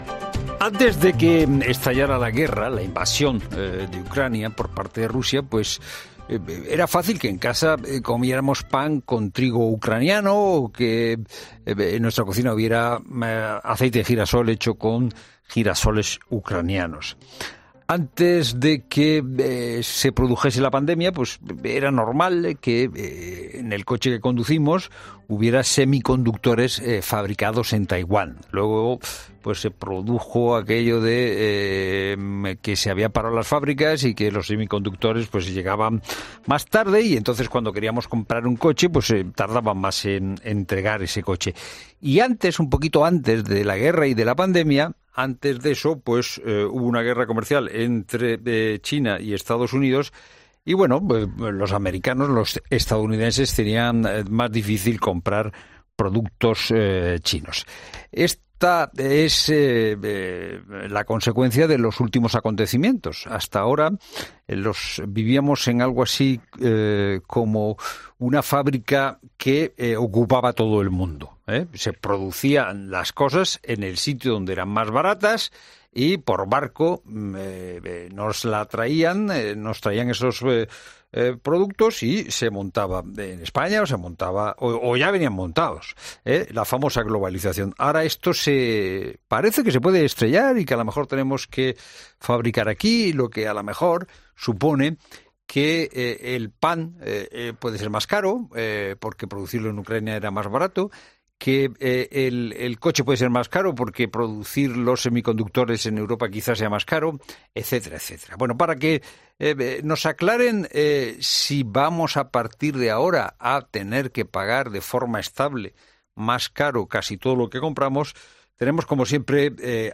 El economista Fernando Trías de Bes explica en La Tarde las posibilidades de que España produzca lo que hasta la guerra de Ucrania compraba